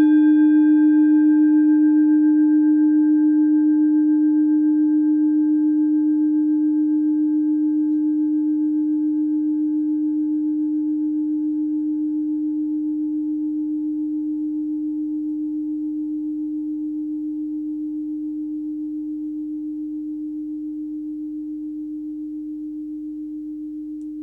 Klangschale Nr.11 Bengalen, Planetentonschale: Eros-Ton
Filzklöppel oder Gummikernschlegel
Die Klangschale hat bei 305.42 Hz einen Teilton mit einer
Die Klangschale hat bei 306.88 Hz einen Teilton mit einer
klangschale-bengalen-11.wav